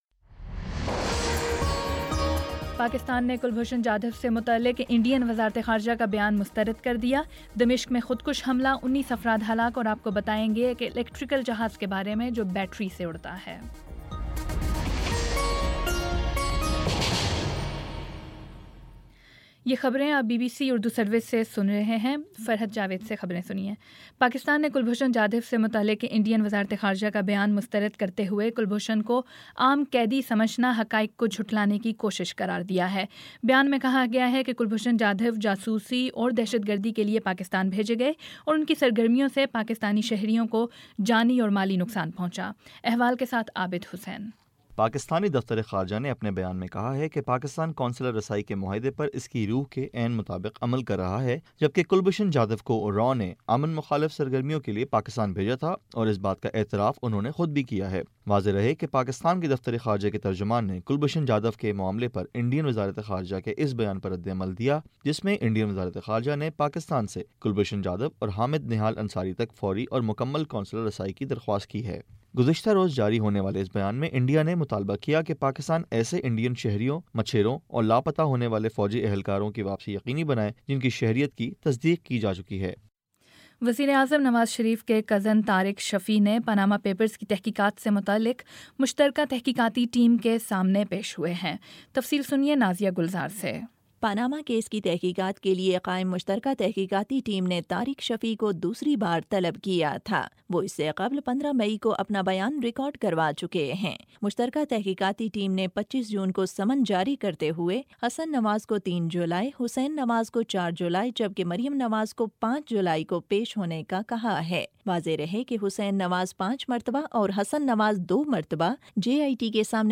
جولائی 02 : شام چھ بجے کا نیوز بُلیٹن